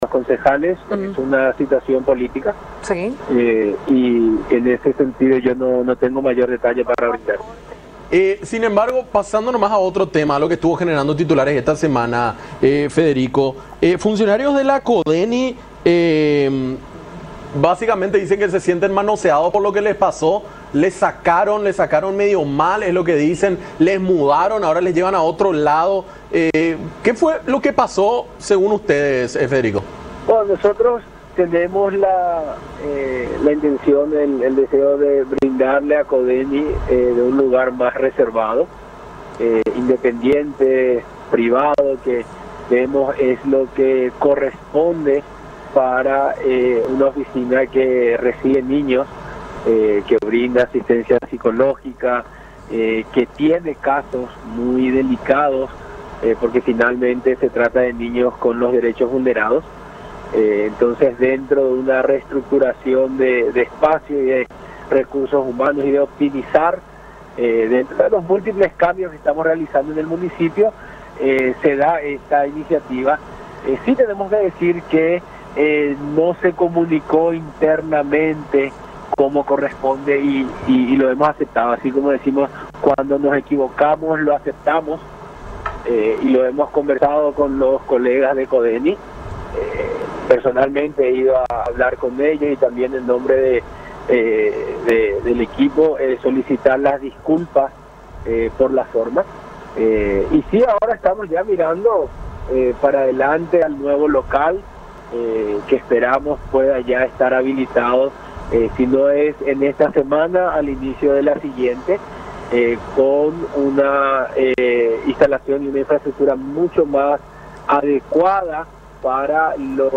Entonces, dentro de una reestructuración de espacios, de recursos humanos y de los múltiples cambios que estamos realizando en el municipio, decidimos llevar adelante esta iniciativa”, explicó Mora en conversación con Nuestra Mañana por Unión TV.